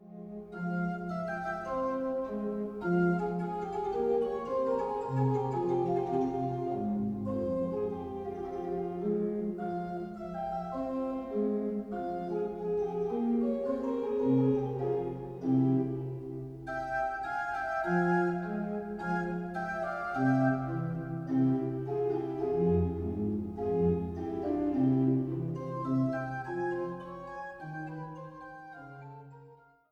Eule-Orgel im Dom zu Zeitz
Orgel